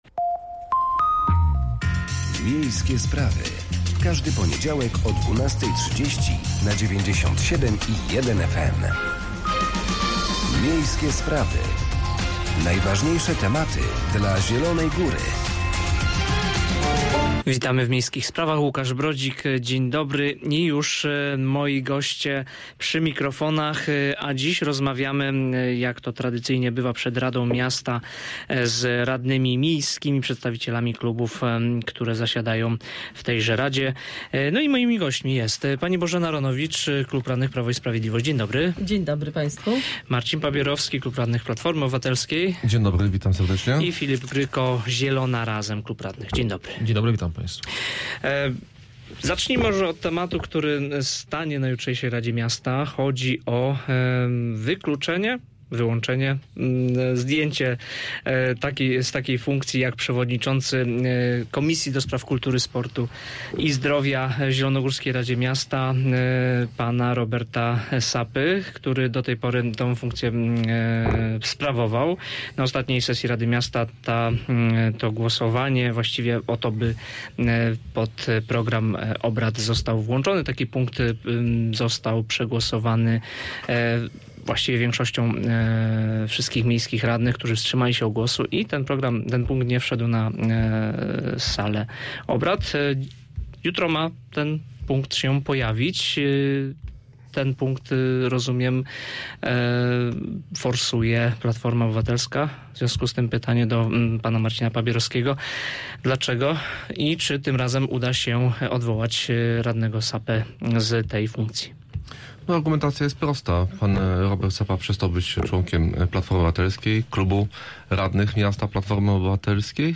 Gośćmi programu są: Bożena Ronowicz – klub radnych PiS w RM, Marcin Pabierowski – klubu radnych PO w RM, Filip Gryko – klub radnych Zielona Razem w RM.